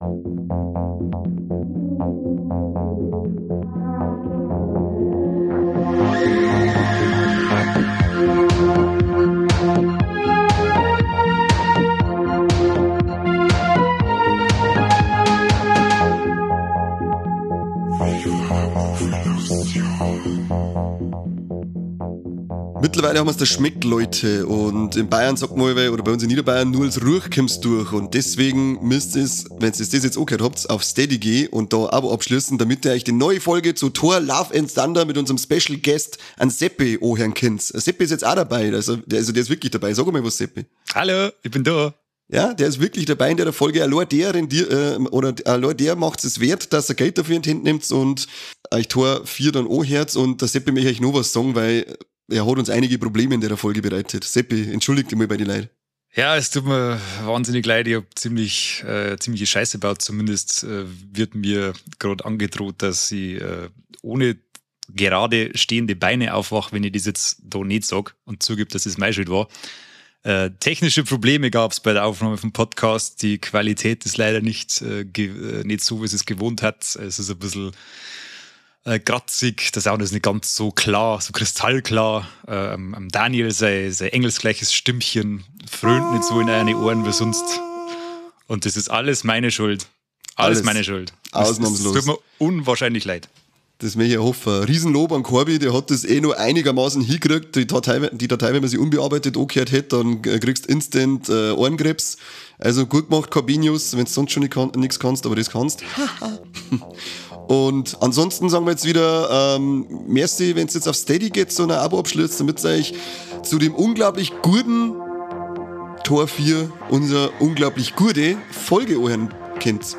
„Viva la Movielución" ist der bayerische Film-Podcast aus Niederbayern – ehrlich, ungefiltert und auf Dialekt.